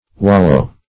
Wallow \Wal"low\, n.